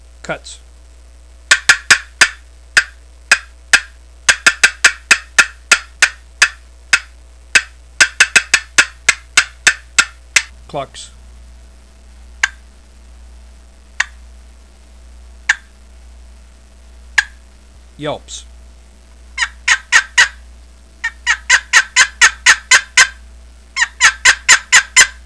Listen to 26 seconds of cutts, clucks, and yelps
An Outrageously Loud Box Call
• Produces extra loud, keen, raspy notes totally different from conventional boxes
southlandscreaminall26.wav